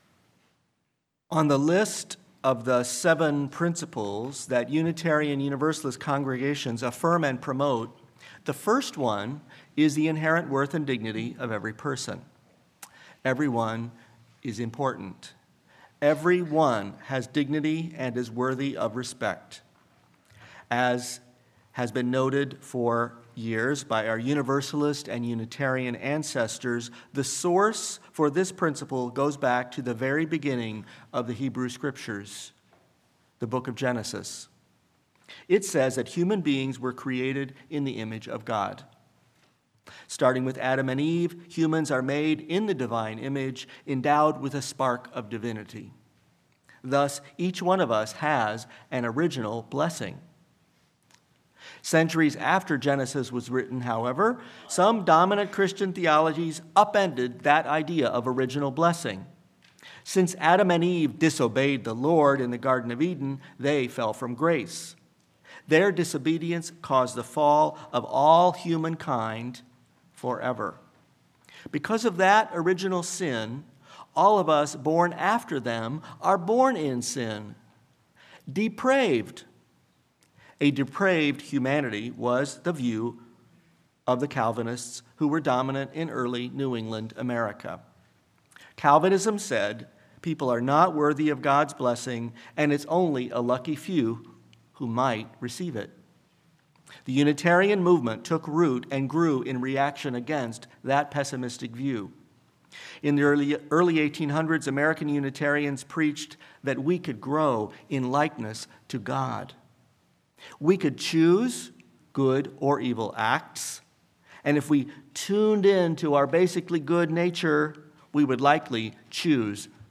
Sermon-What-Good-is-a-Human-Being.mp3